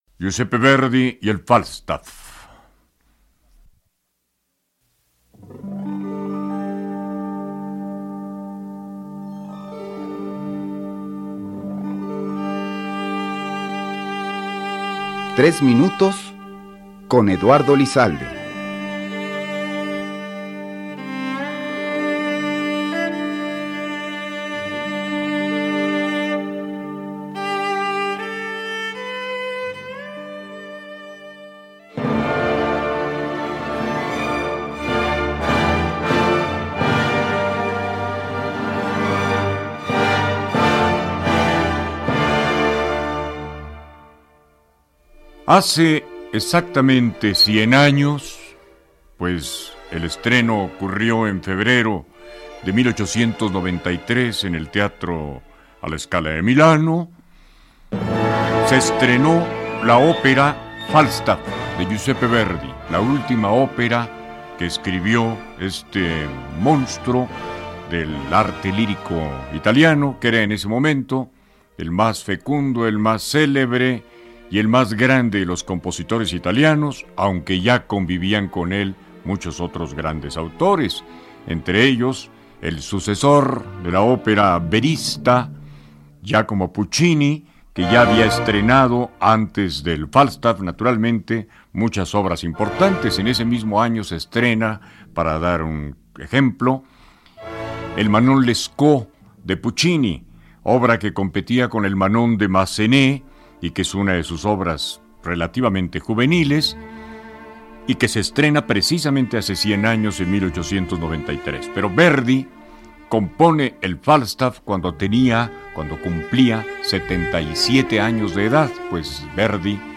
Escucha un comentario sobre Verdi y su última ópera “Falstaff” en el programa de Eduardo Lizalde “Tres minutos con…”, transmitido en 1993.